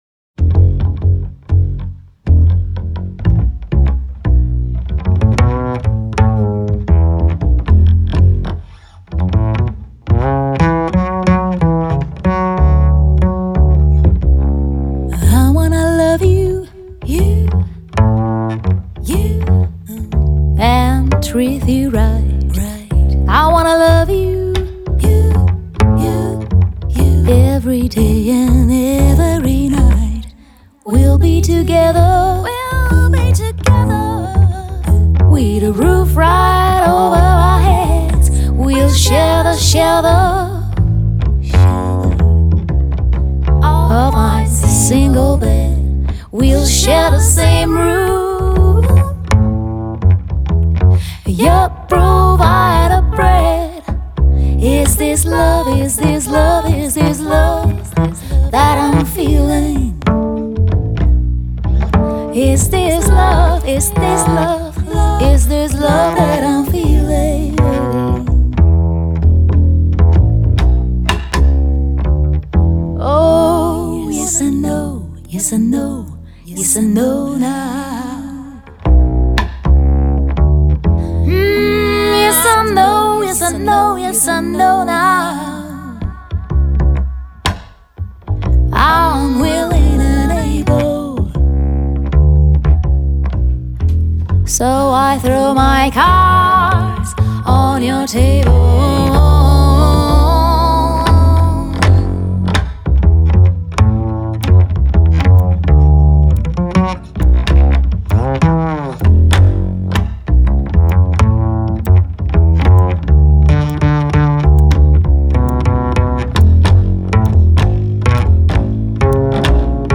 Жанр: Jazz/Pop.